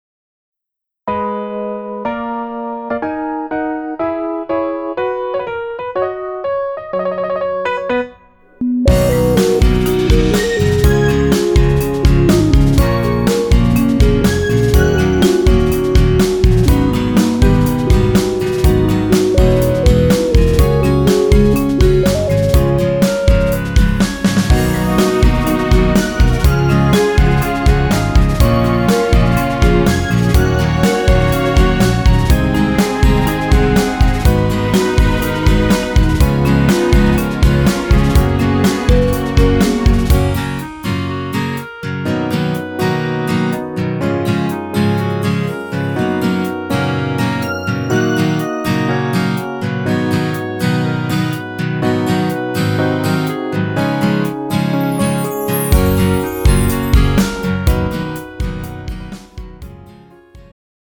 음정 남자-2키
장르 축가 구분 Pro MR